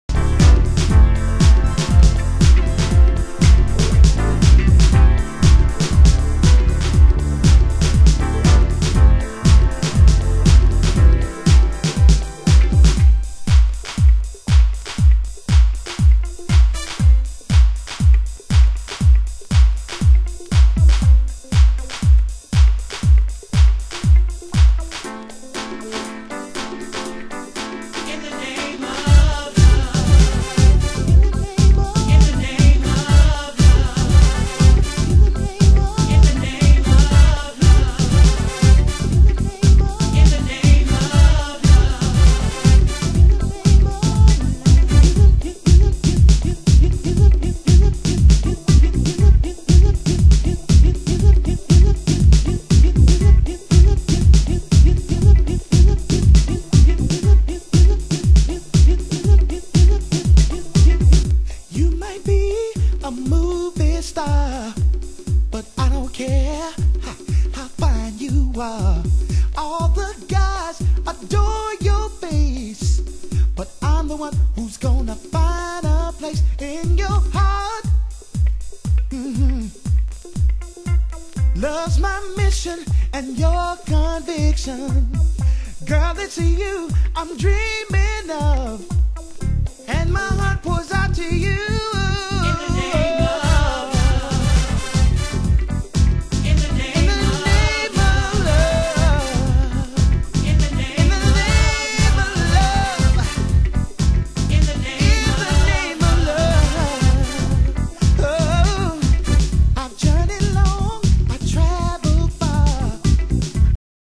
Deep House Mix
盤質：少しチリパチノイズ有/A1前半部に軽い線のスレ 有/Mastered M.J.R.！